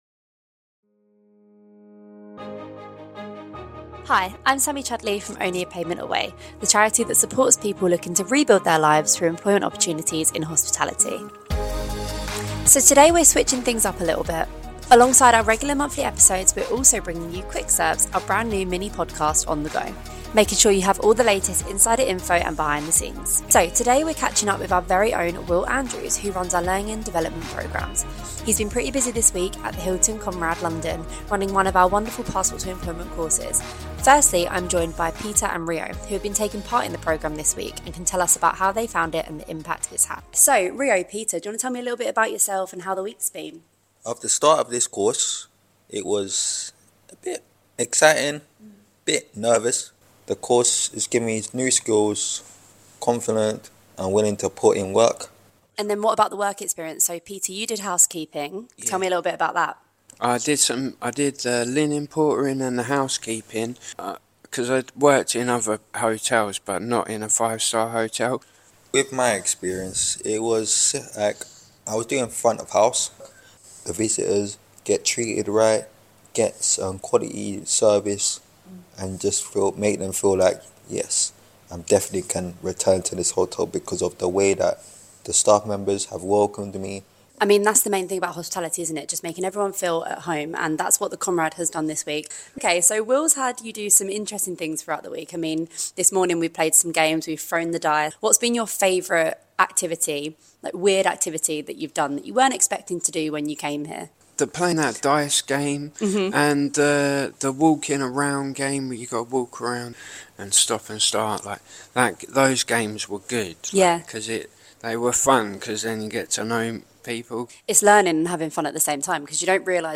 Quick Serves: The Only A Pavement Away Mini Podcast On-the-Go Alongside our monthly Serving Success episodes, Quick Serves brings you bite-sized conversations to keep you inspired, connected and up to date.